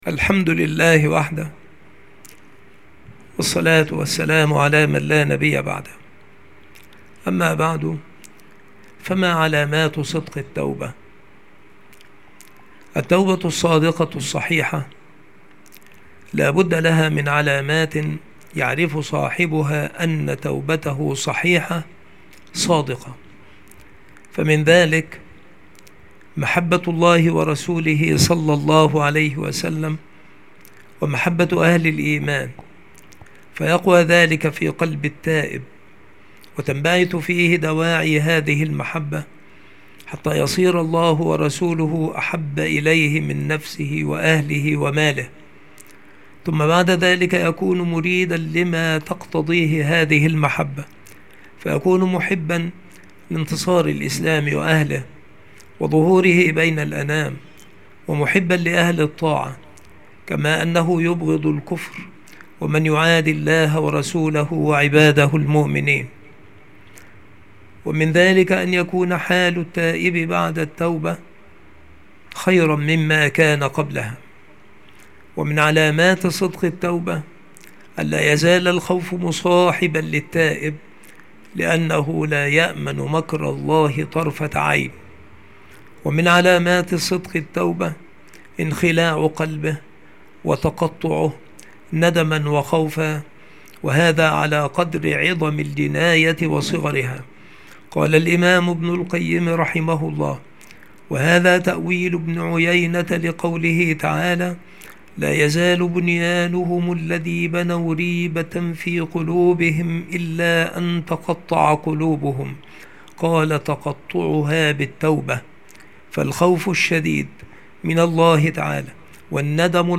مقاطع موجزة
مكان إلقاء هذه المحاضرة المكتبة - سبك الأحد - أشمون - محافظة المنوفية - مصر